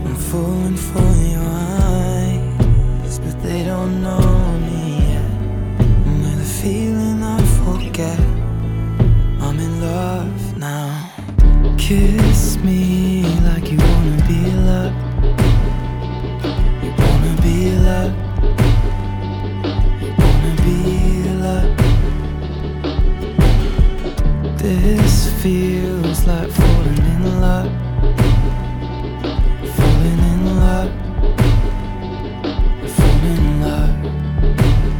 Жанр: Рок / Альтернатива / Фолк